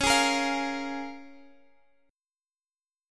C#6 Chord (page 5)
Listen to C#6 strummed